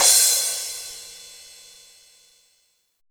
POP CRASH.wav